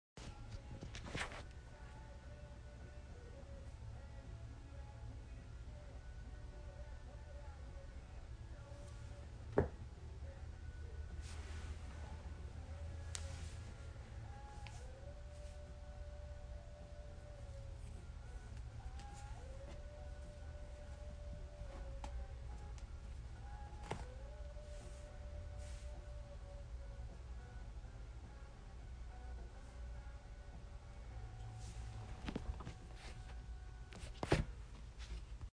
A Quite Drive Downtown (don’t worry I had my brother record it)
Sounds in Clip- The sound of the wind blowing hard against my car, the suffiling as I try not to make too much noise with my body, the click of my turn signal.